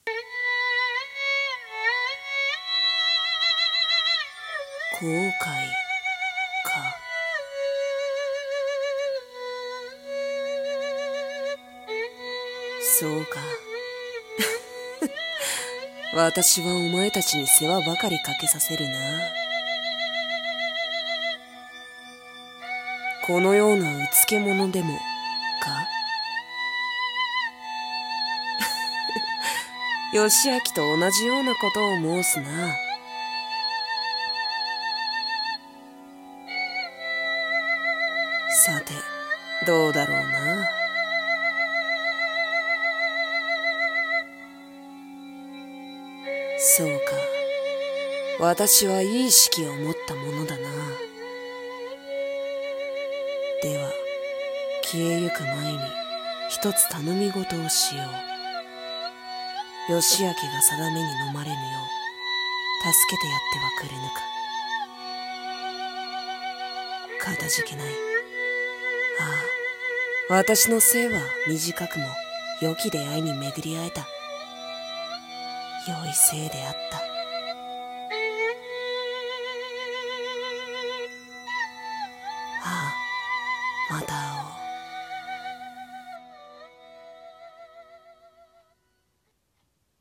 【声劇台本】斯くも想いし死出の旅
和風 BGM 寂しい